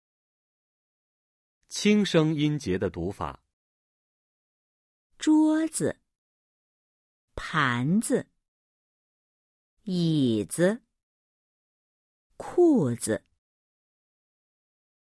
1. 轻声音节的读法 📀 10-4
Cách đọc âm tiết mang thanh nhẹ